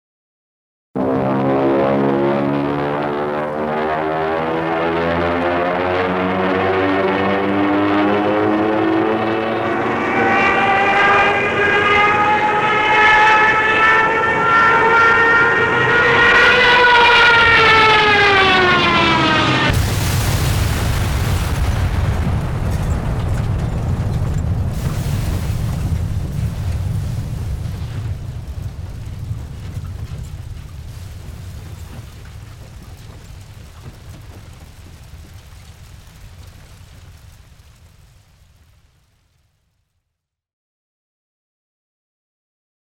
Звуки самолетов
Гул пикирующего самолета